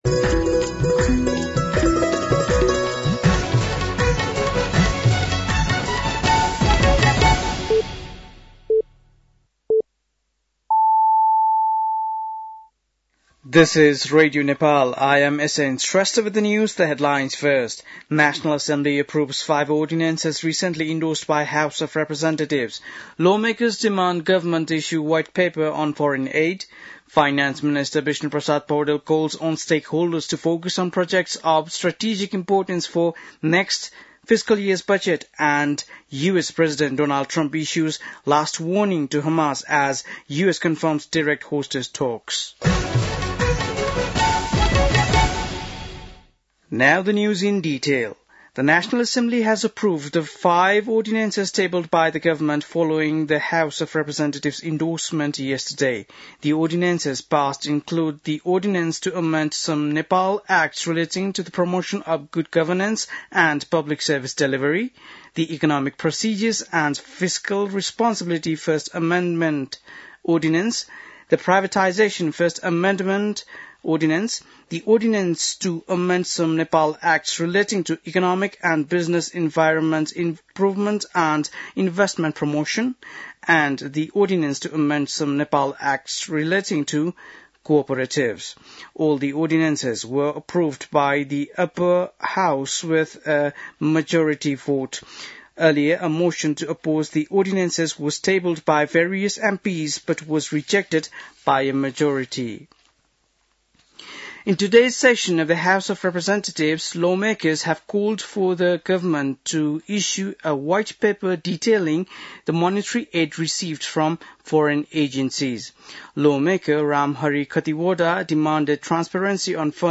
बेलुकी ८ बजेको अङ्ग्रेजी समाचार : २३ फागुन , २०८१